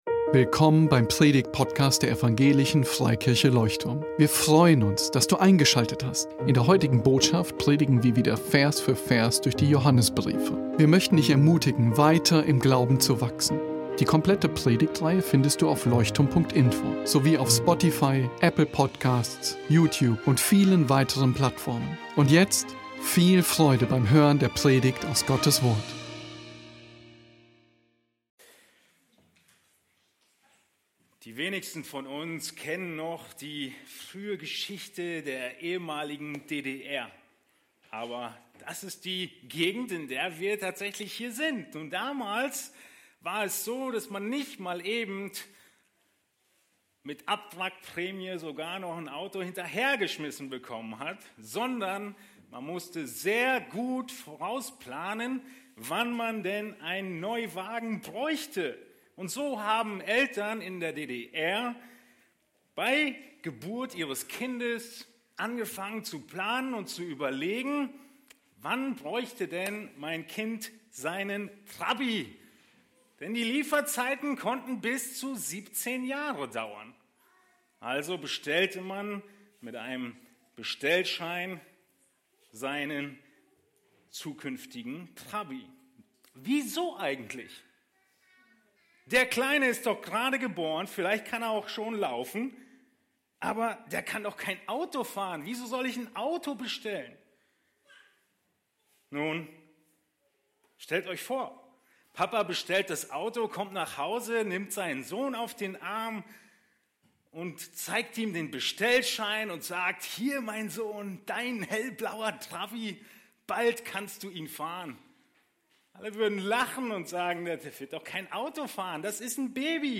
Besuche unseren Gottesdienst in Berlin.